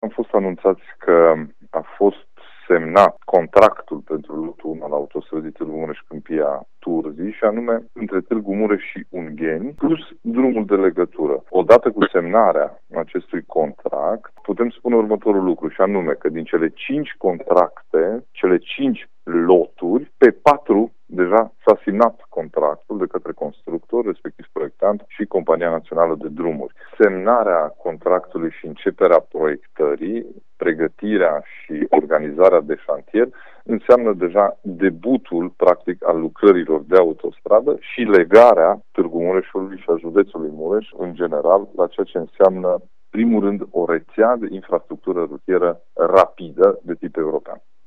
Președintele Consiliului Județean Mureș, Ciprian Dobre: